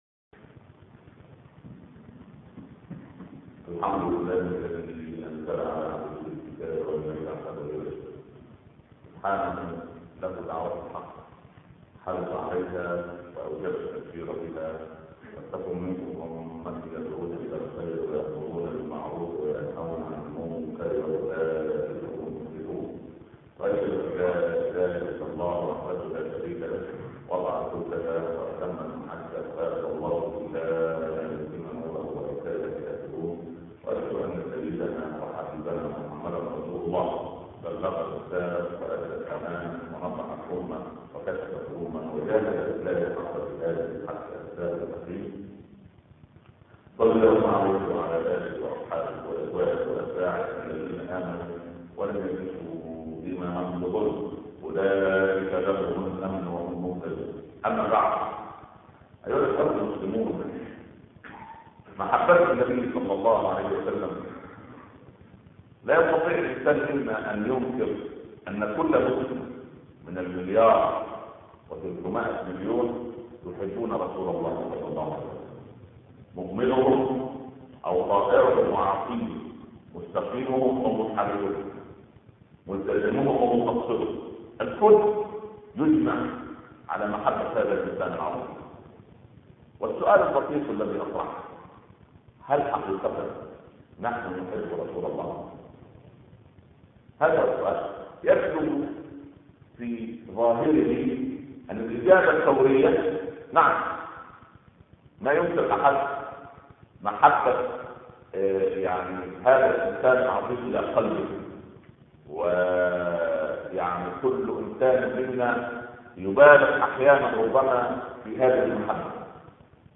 khotab-download-87301.htm